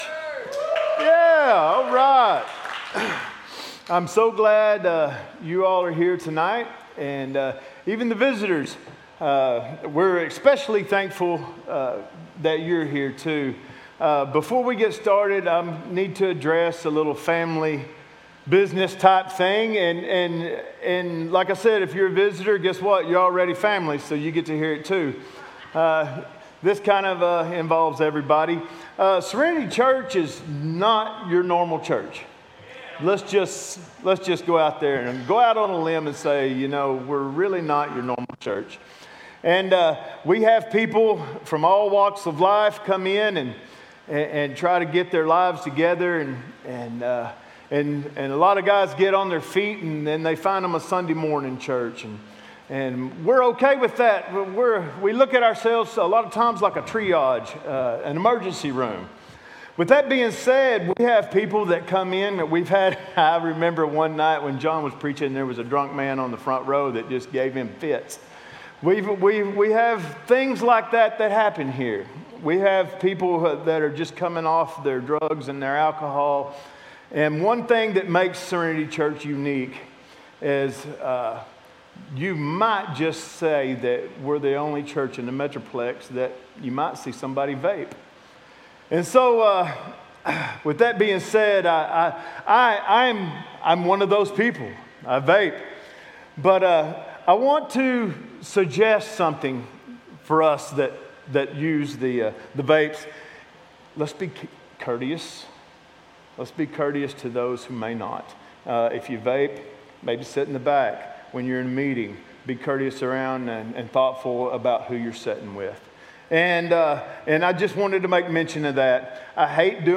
Sermons | Serenity Church